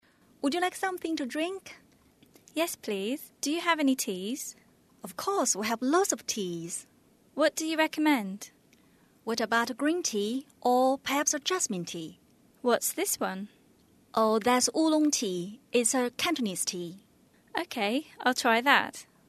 英语初学者口语对话第45集：你想喝点什么？
english_29_dialogue_2.mp3